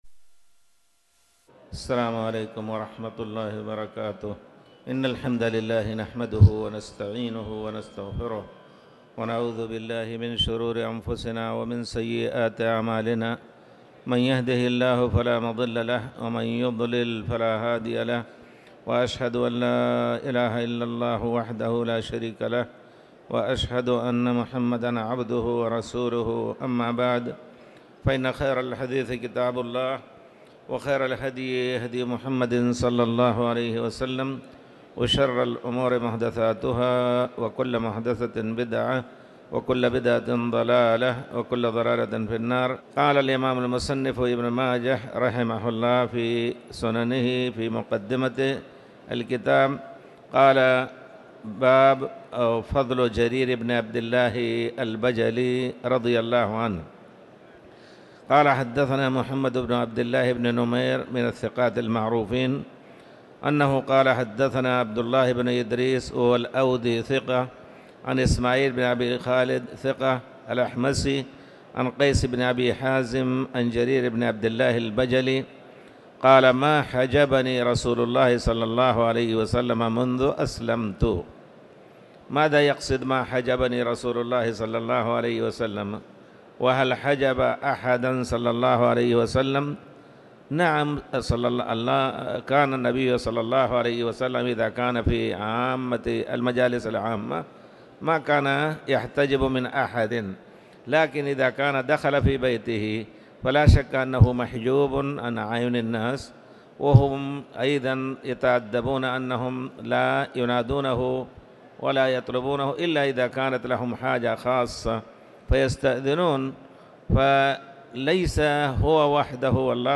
تاريخ النشر ١٥ ذو القعدة ١٤٤٠ هـ المكان: المسجد الحرام الشيخ